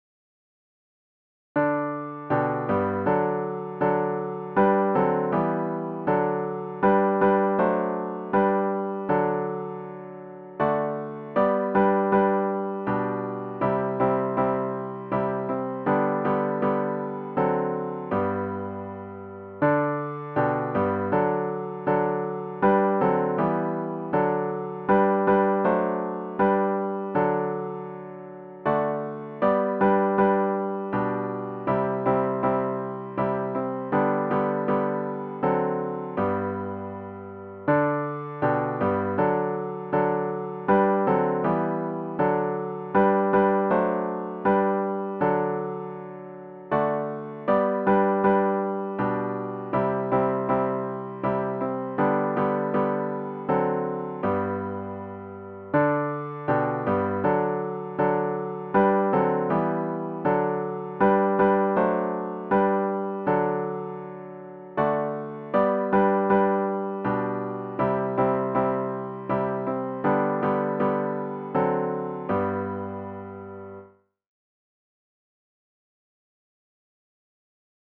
OPENING HYMN   “O for a Thousand Tongues to Sing”   Glory to God hymn 610   (Verses 1-4)
zz-610-O-for-a-Thousand-Tongues-to-Sing-piano-only.mp3